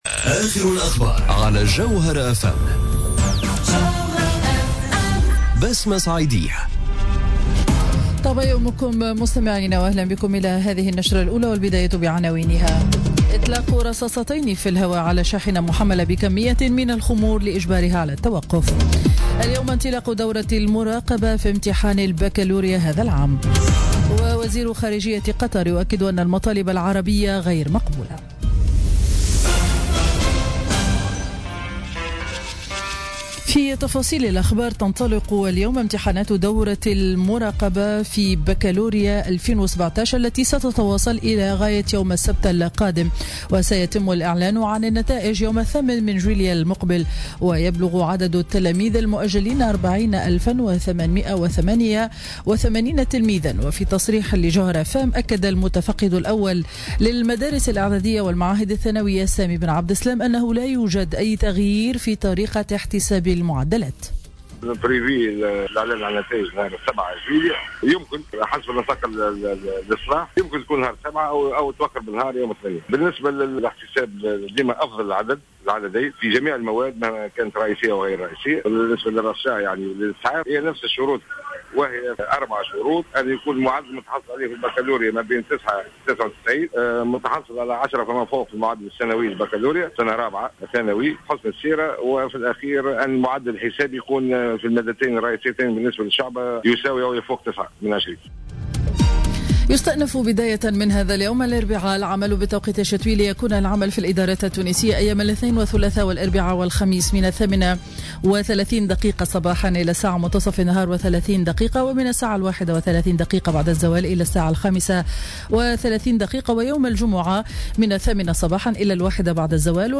نشرة أخبار السابعة صباحا ليوم الأربعاء 28 جوان 2017